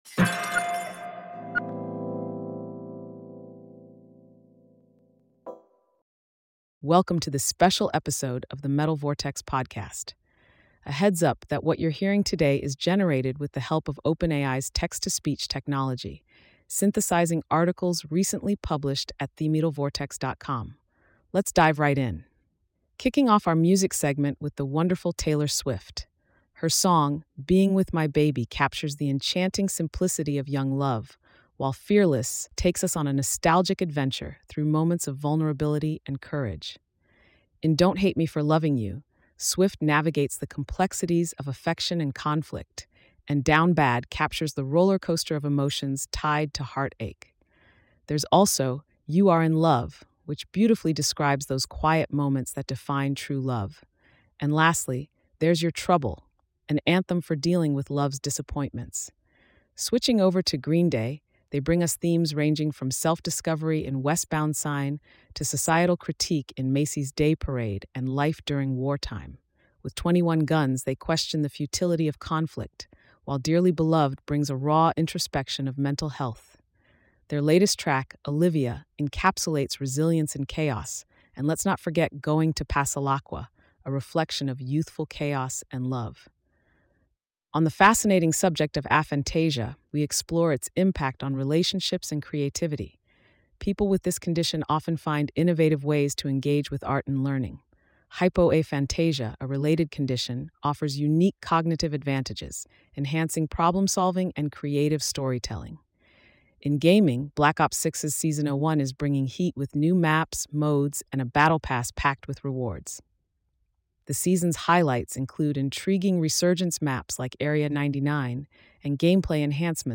In this special episode, we’re bringing you the latest insights and updates across music, technology, gaming, and more—all generated with the help of OpenAI’s text-to-speech technology.